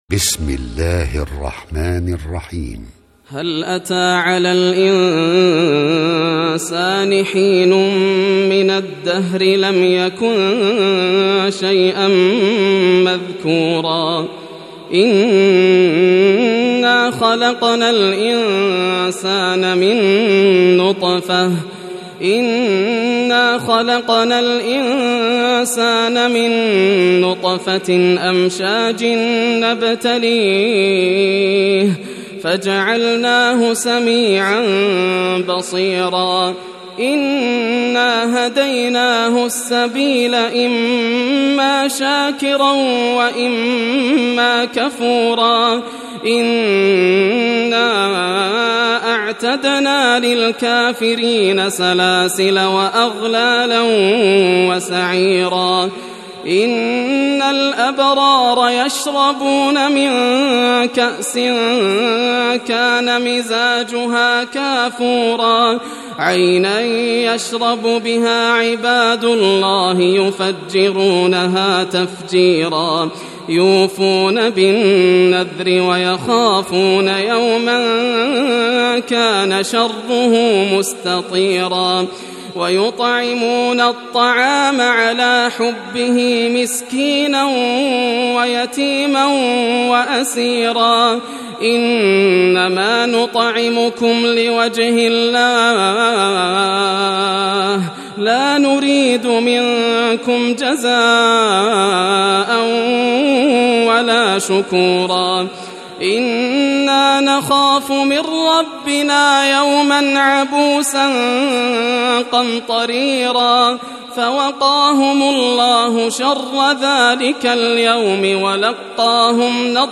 سورة الإنسان > المصحف المرتل للشيخ ياسر الدوسري > المصحف - تلاوات الحرمين